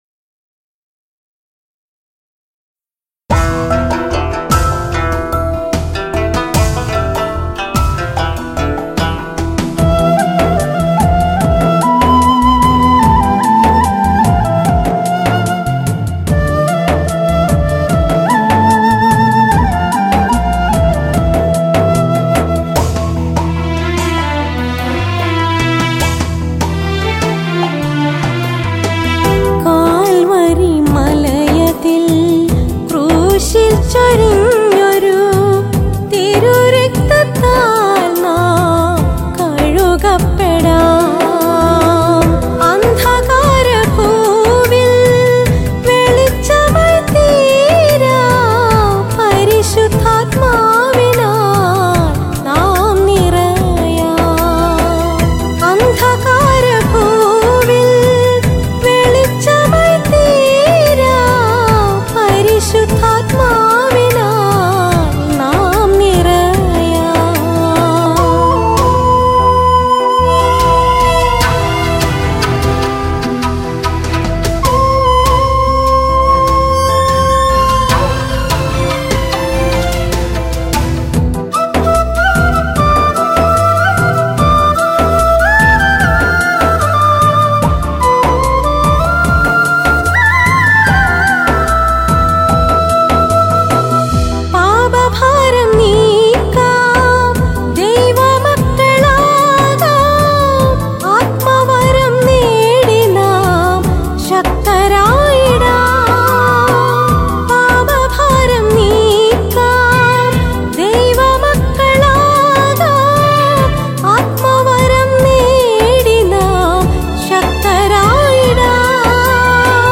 Christian Devotional Songs & Video Albums